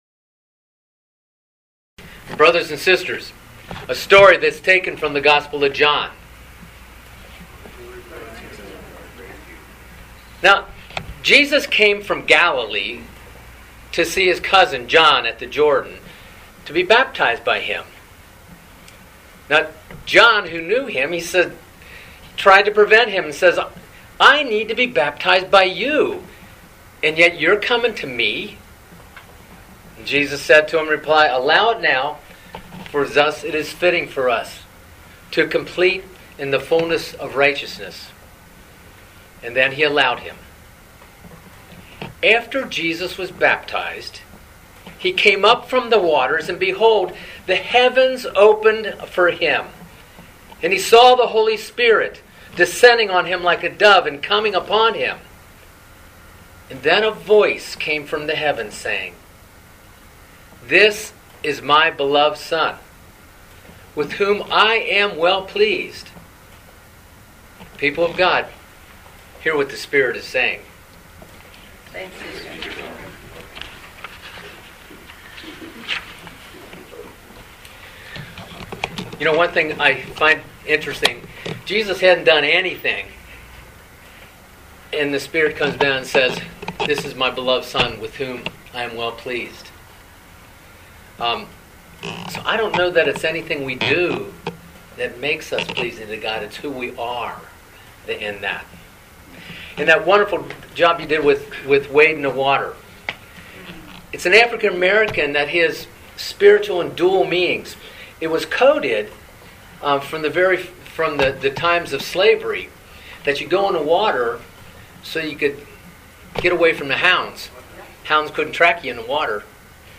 Living Beatitudes Community Homilies: Child of God